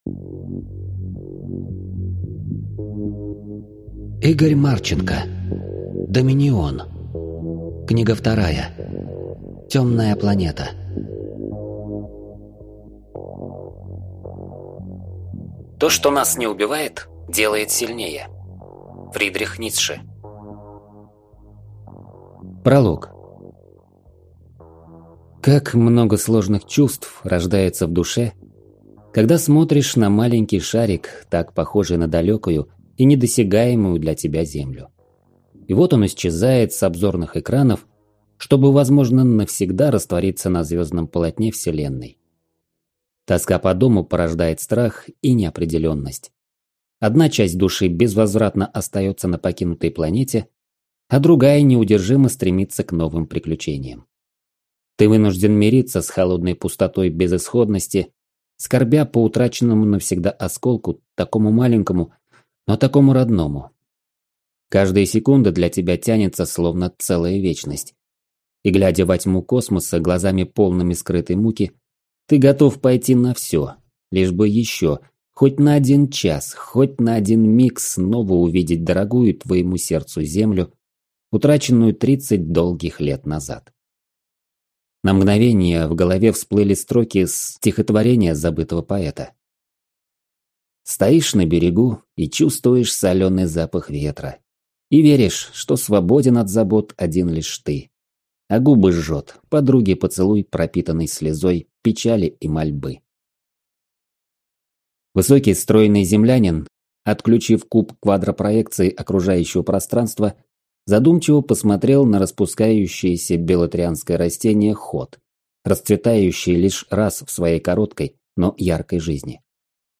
Аудиокнига Темная планета | Библиотека аудиокниг